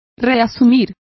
Complete with pronunciation of the translation of resumes.